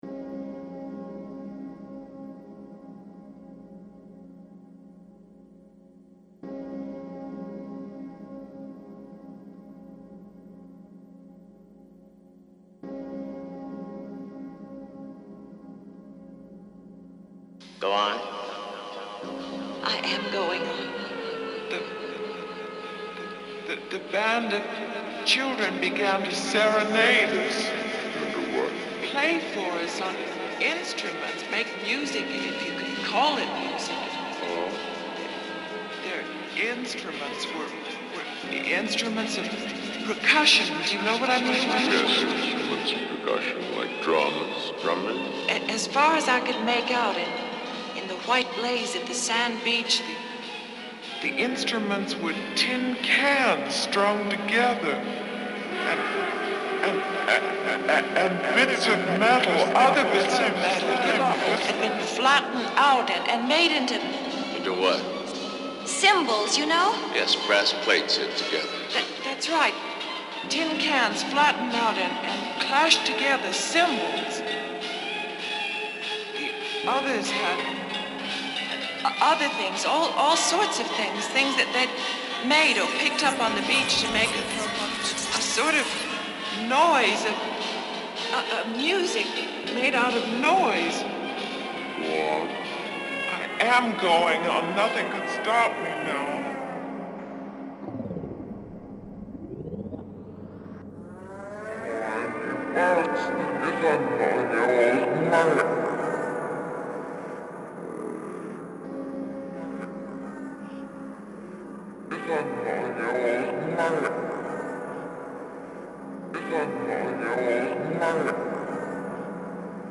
A meditation on skips and cycles.